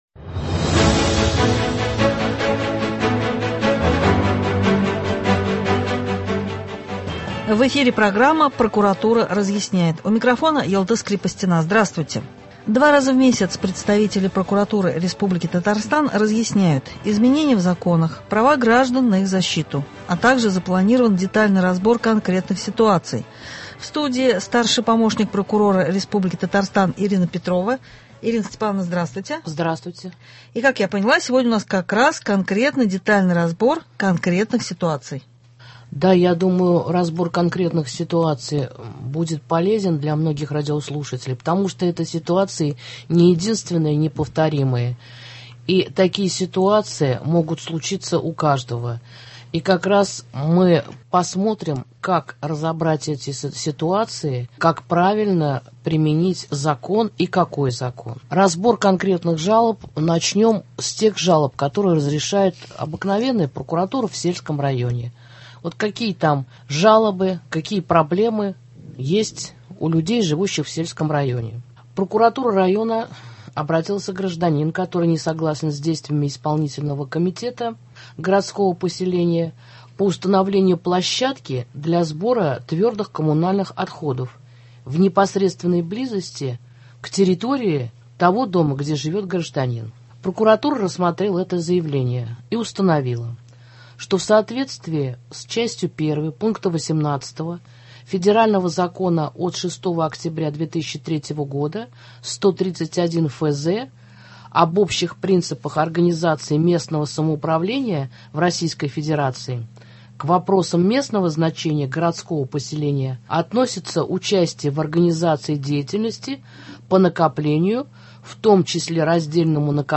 Два раза в месяц представители прокуратуры республики Татарстан разъясняют: изменения в законах, права граждан на их защиту, также запланирован детальный разбор конкретных ситуаций. В студии